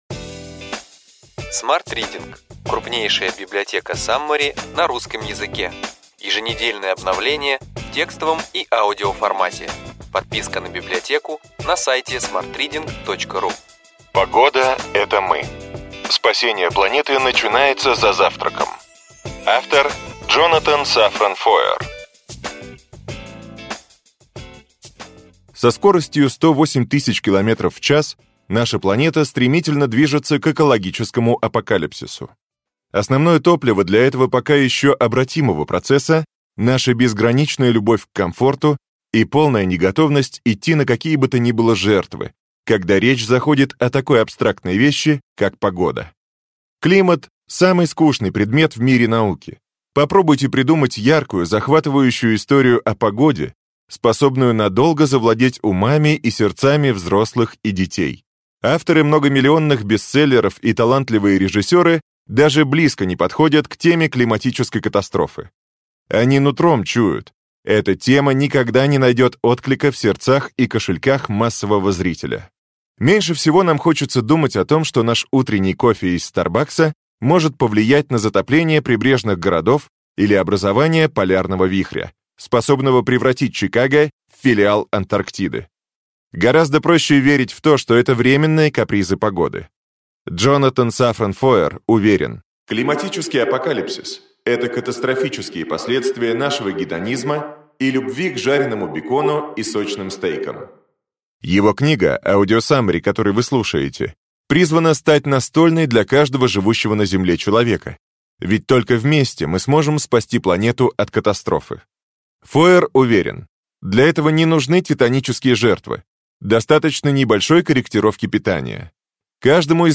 Читает аудиокнигу